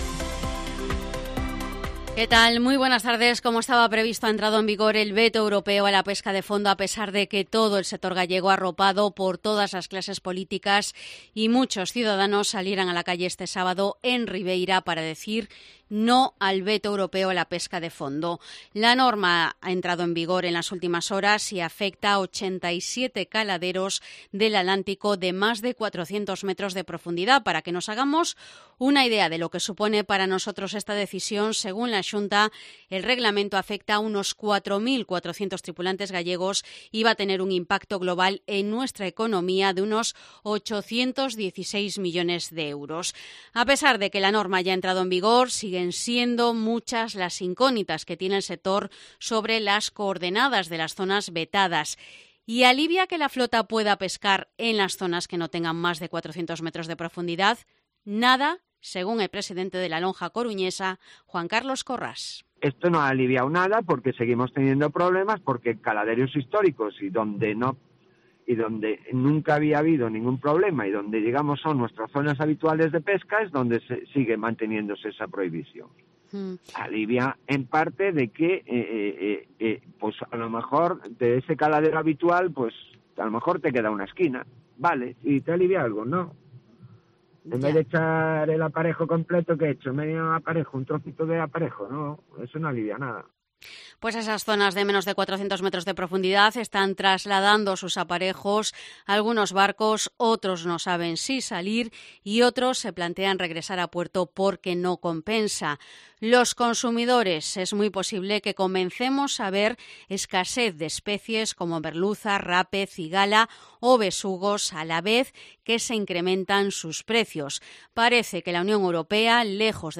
Informativo mediodía COPE Coruña lunes, 10 de octubre de 2022